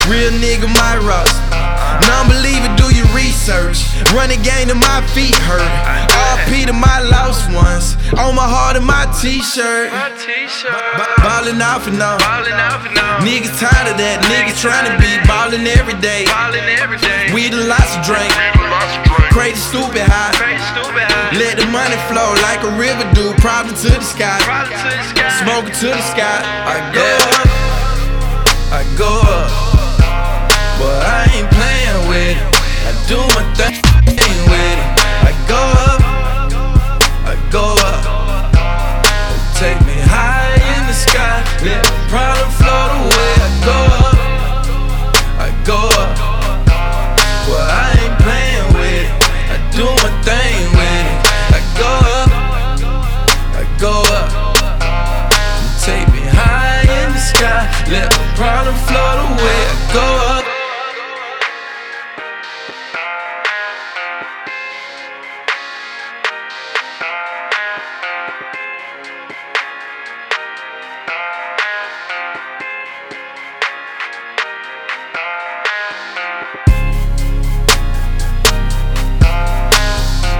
Hiphop
His genre of music performed is Urban/Pop.